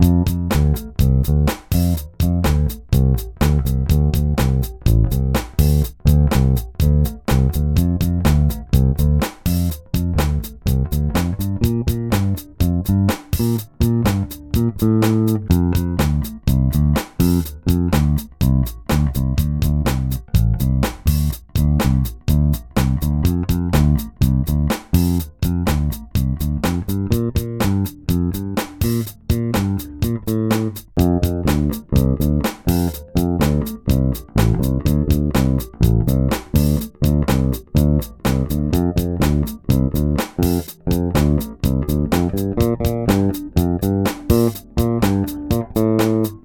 vigier_arpege_iii_6_senr_026_passive.wav